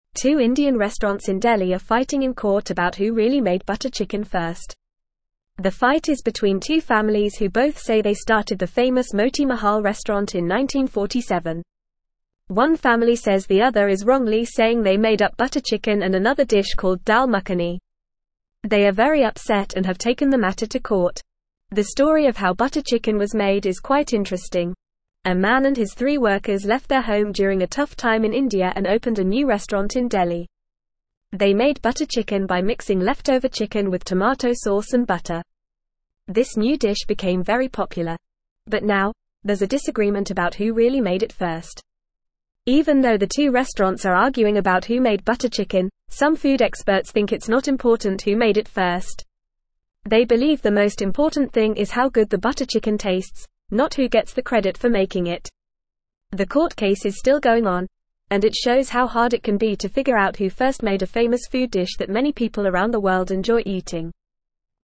Fast
English-Newsroom-Lower-Intermediate-FAST-Reading-Butter-Chicken-Battle-Who-Made-It-First.mp3